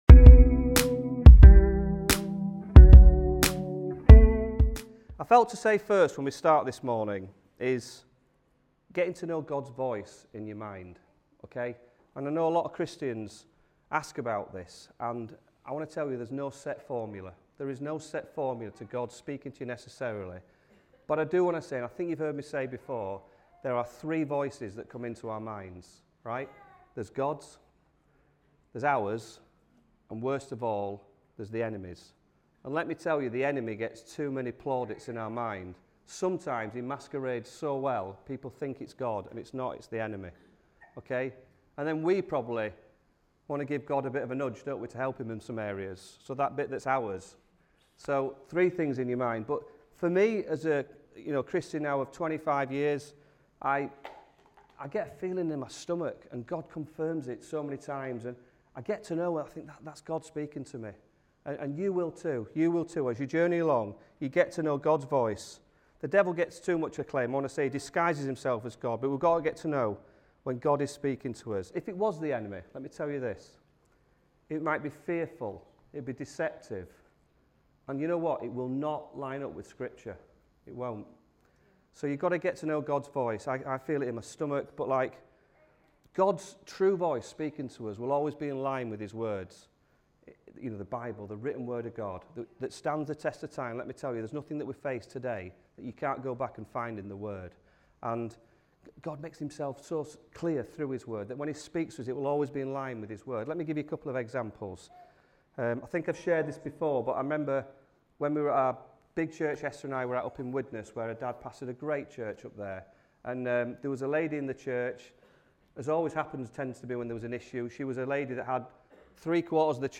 Sunday Messages Manage Your Mind